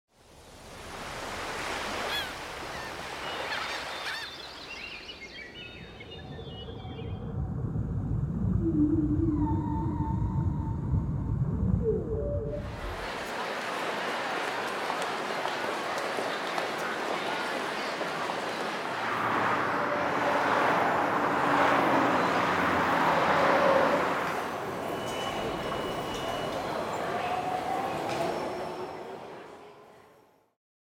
游戏音效[环境类] – 深圳声之浪潮文化传播有限公司
标签 写实
【环境类】环境类音效是用于营造特定氛围或场景的声音效果，能够增强沉浸感，使听众仿佛置身于特定环境中。